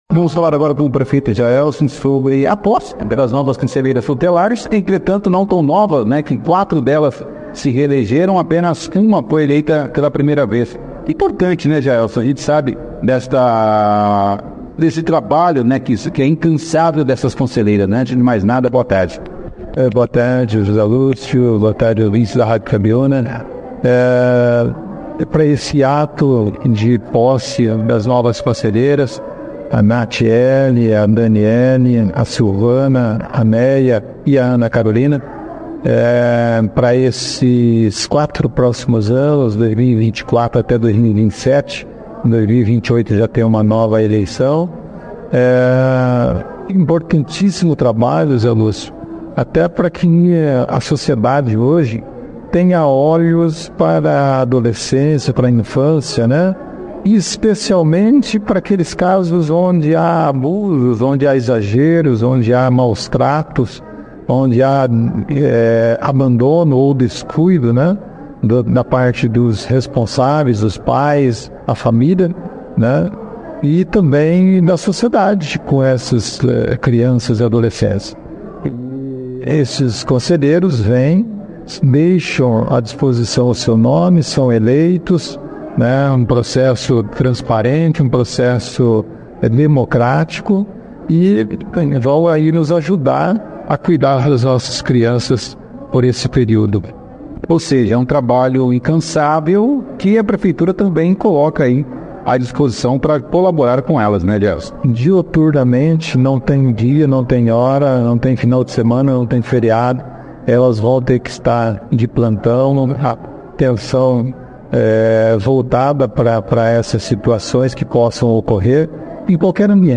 Na entrevista reafirmaram o comprometimento de desempenhar suas funções de forma dedicada e responsável em prol da proteção dos direitos das crianças e adolescentes no município de Bandeirant